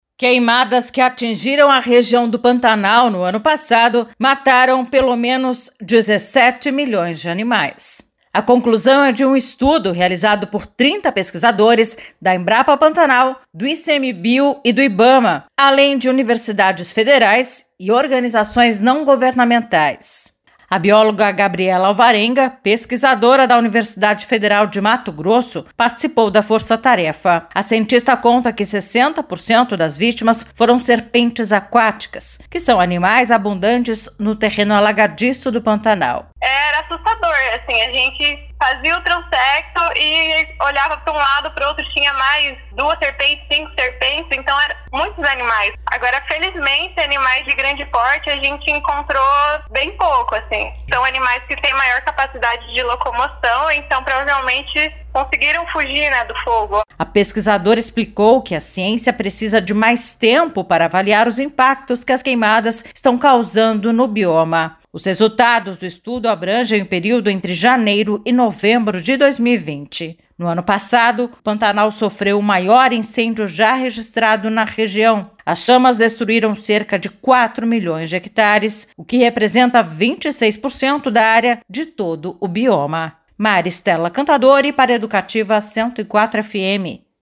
Confira as informações com a repórter